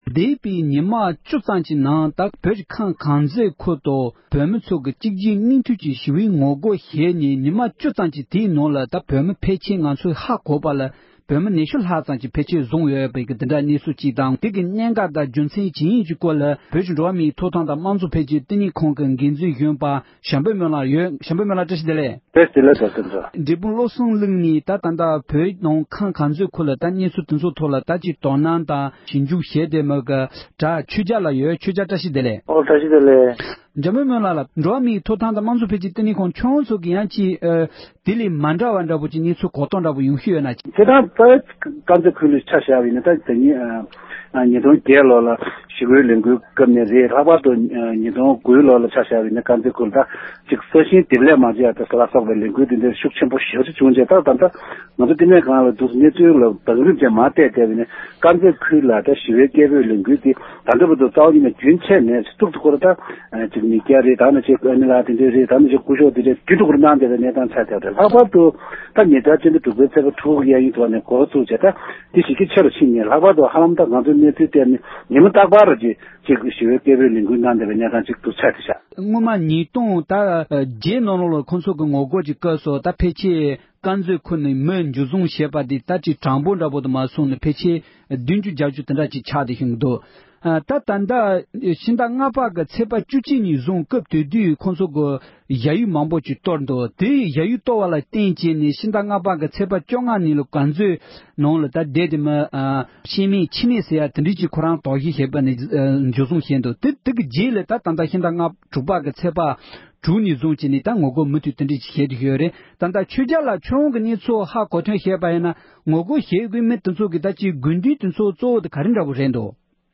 བོད་ཀྱི་ཁམས་དཀར་མཛེས་ཁུལ་དུ་བོད་མི་ཚོས་མུ་མཐུད་ཞི་བའི་ངོ་རྒོལ་སྤེལ་བཞིན་ཡོད་པའི་ཐད་གླེང་མོལ།